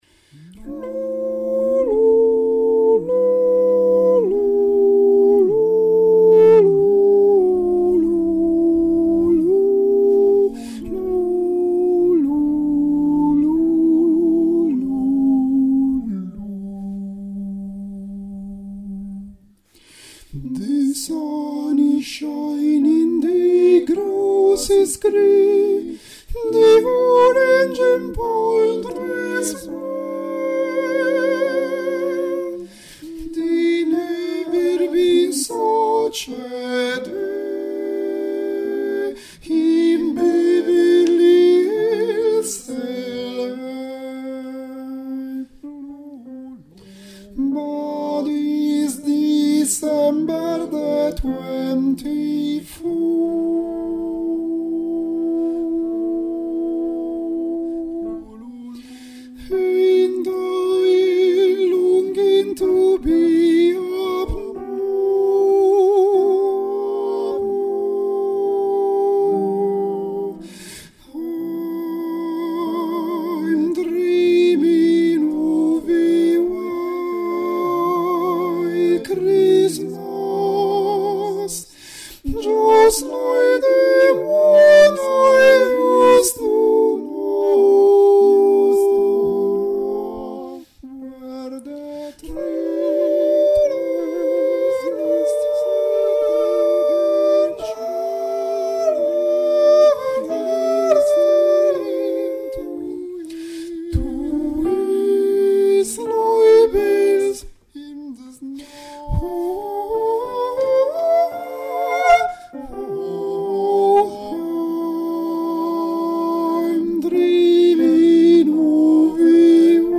Parti per i soprani primi
White Christmas (sopr. I)